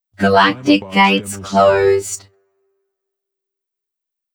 “Galactic Gates closed” Clamor Sound Effect
Can also be used as a car sound and works as a Tesla LockChime sound for the Boombox.
GalacticGatesClosed-Demo.wav